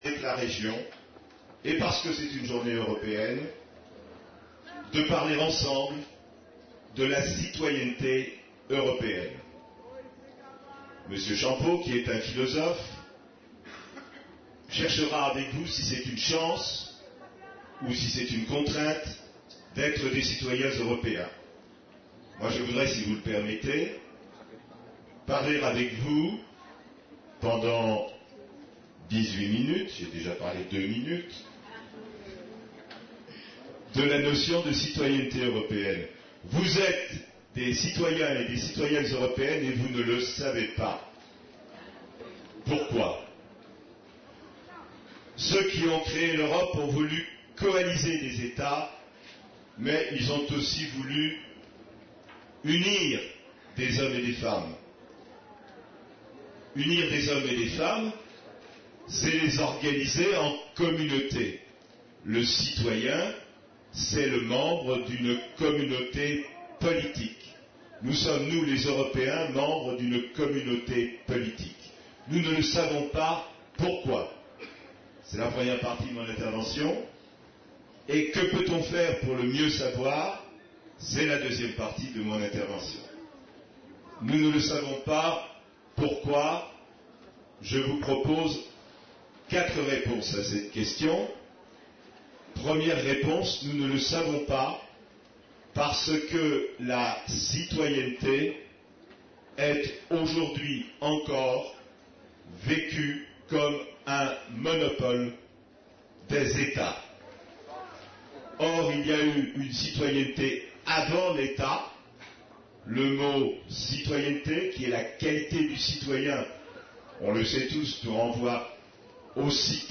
Une conférence de l'UTLS au Lycée
Festival des lycéens ( Marsac sur l'Isle, 24)